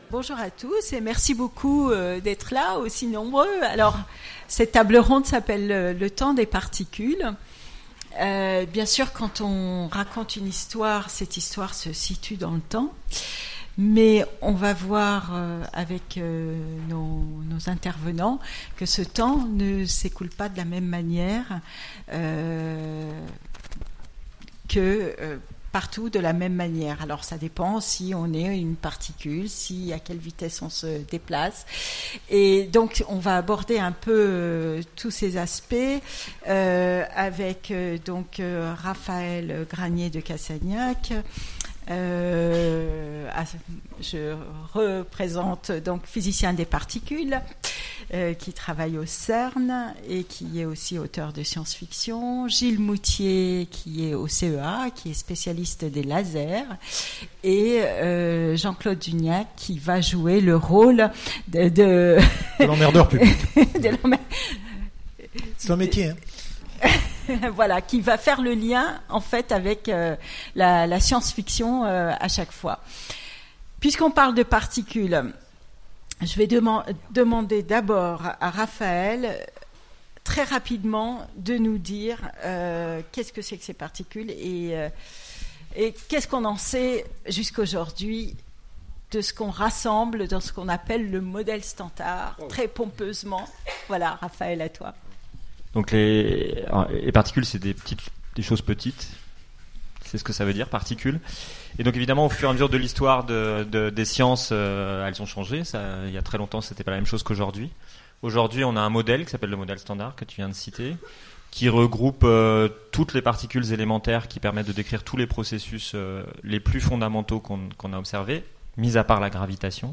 Utopiales 2017 : Conférence Le temps des particules
Conférence